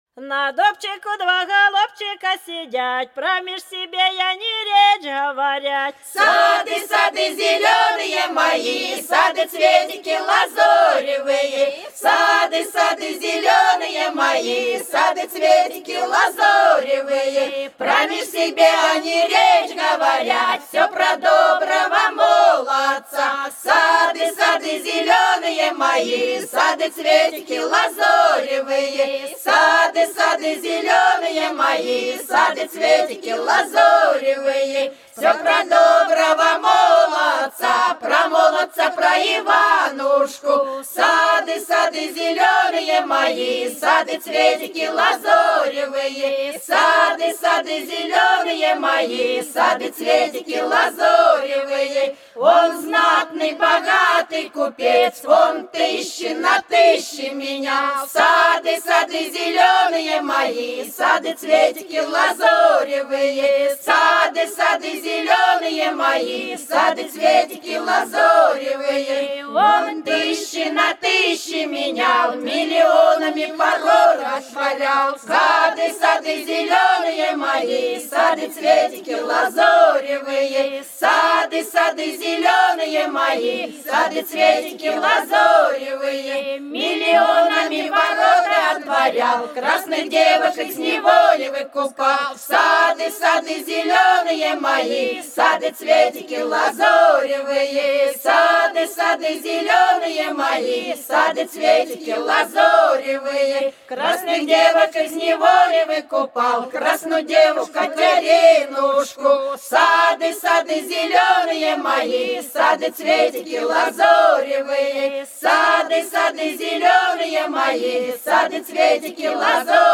За речкою диво На дубчику два голубчика сидят - свадебная (с. Русская Буйловка)
17_На_дубчику_два_голубчика_сидят_-_свадебная.mp3